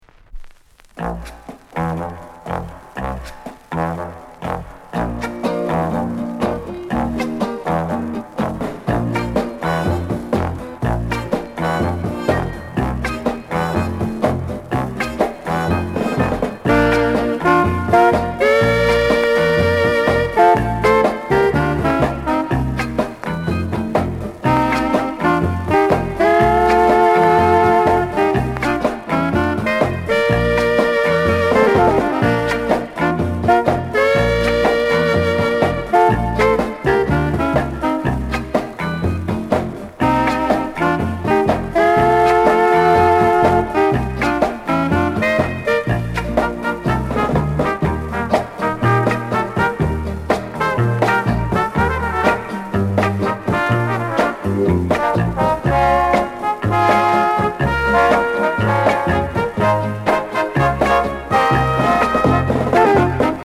SOUND CONDITION VG(OK)
NICE INST